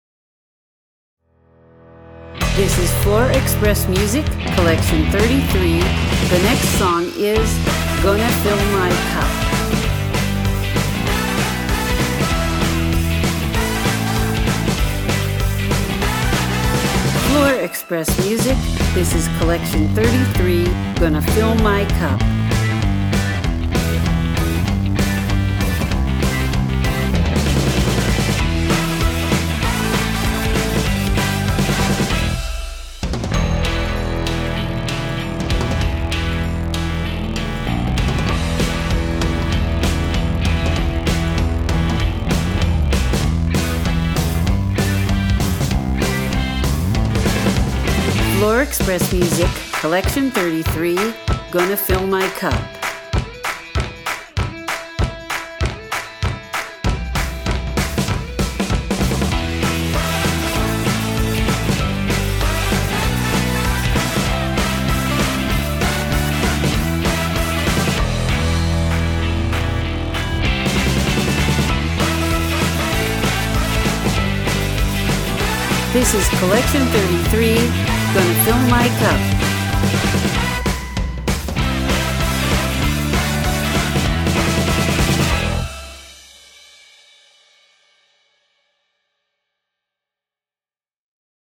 • Swing
• Big Band